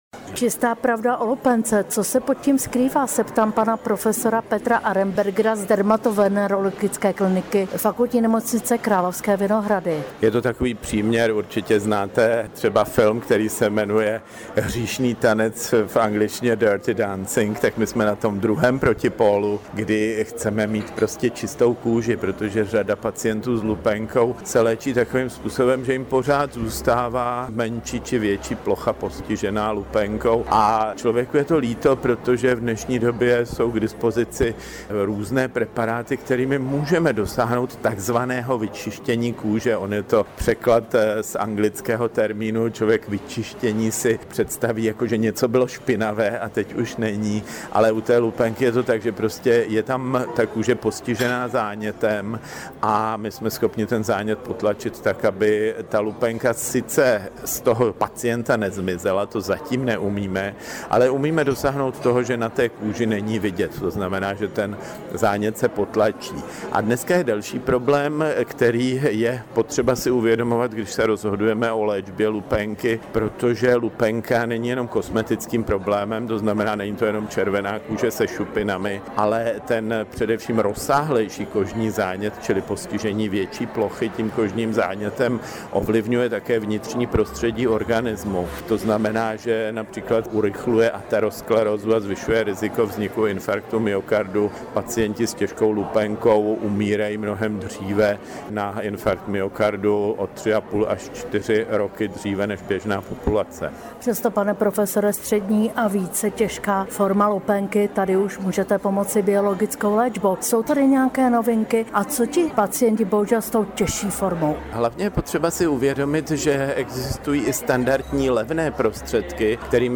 Rozhovor o lupénce